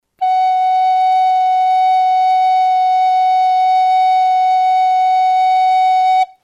A nota FA# (Solb). Dixitación recomendable.